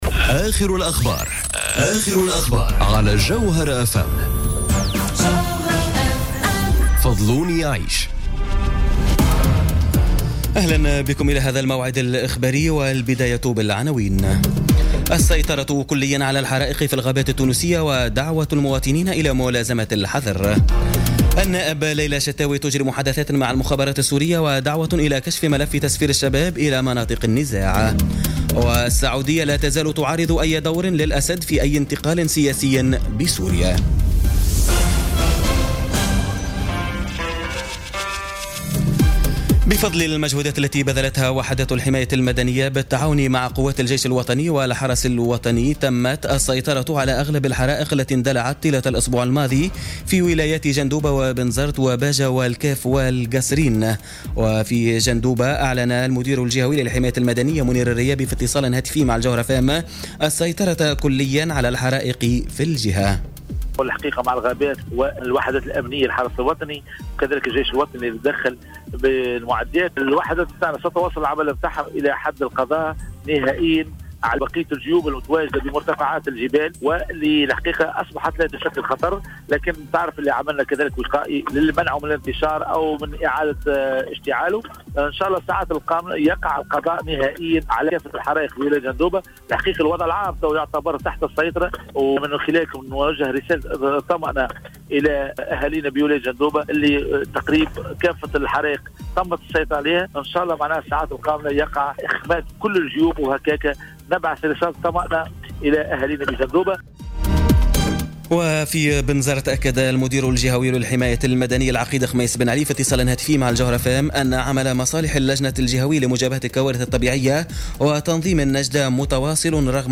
نشرة أخبار منتصف الليل ليوم الاثنين 7 أوت 2017